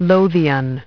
Транскрипция и произношение слова "lothian" в британском и американском вариантах.